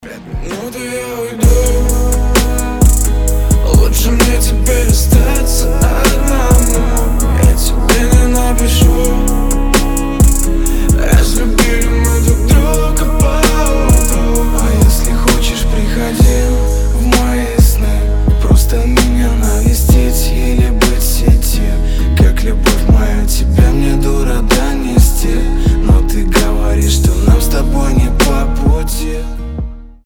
• Качество: 320, Stereo
гитара
мужской вокал
лирика
грустные